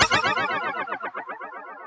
Bounce Sound Effect
Download a high-quality bounce sound effect.
bounce-2.mp3